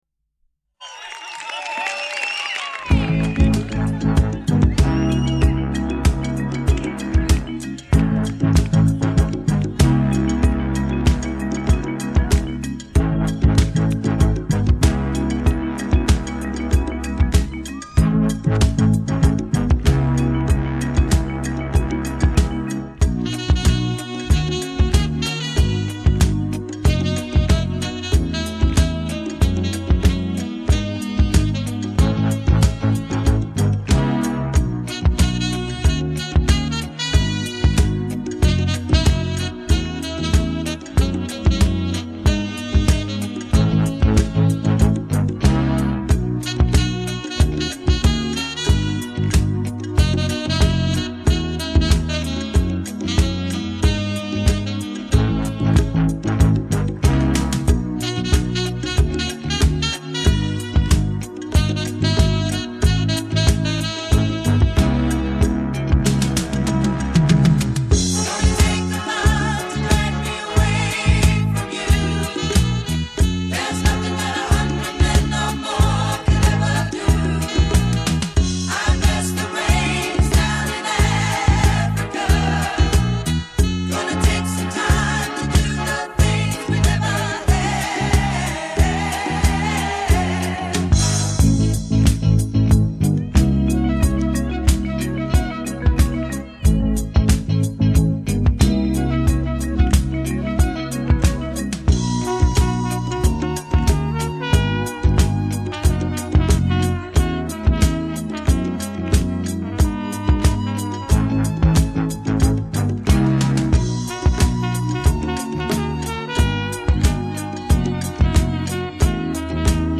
AROUND THE WORLD WITH HAPPY MUSIC
（卡带转WAV）
本辑中部分曲目采用合唱、和声与乐队配合，构成了一道美丽的风景。